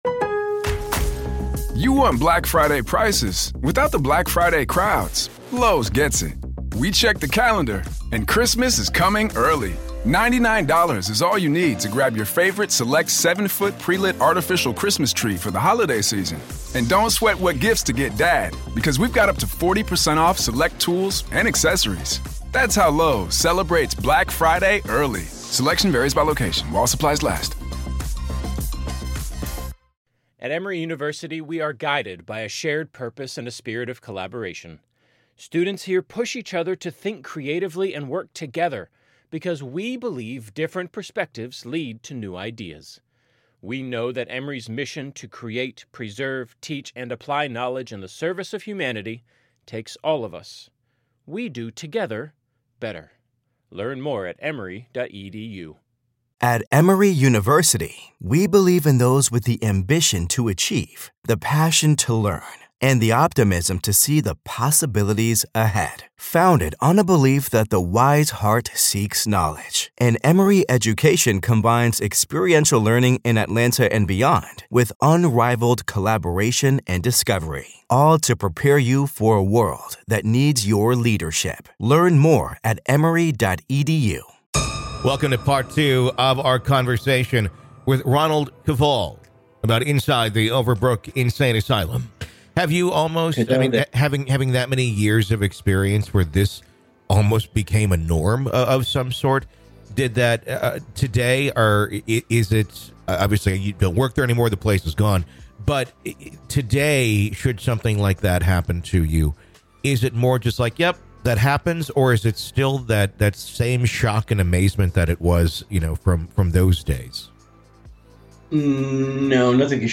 From time warps to conversations with patients who have passed, and much more. This is Part Four of our conversation.